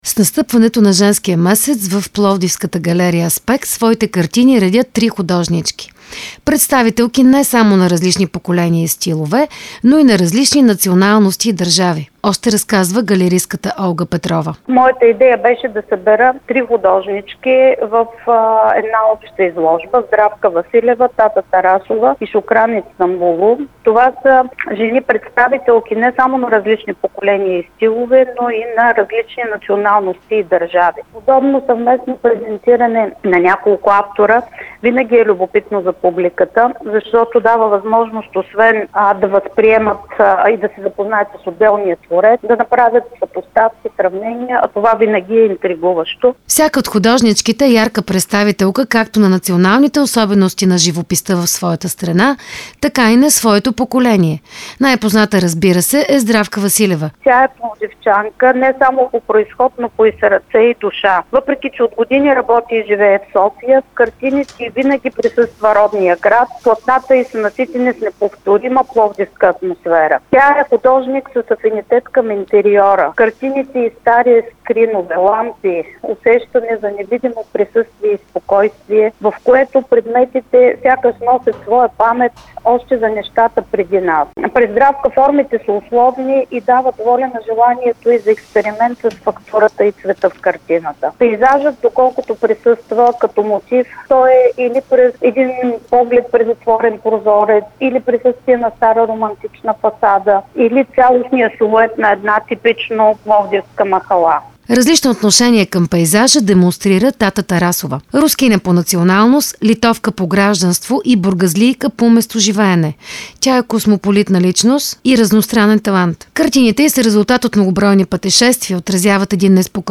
Reportaz-izlozba-Zenski-svetove-3-37.mp3